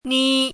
chinese-voice - 汉字语音库
ni1.mp3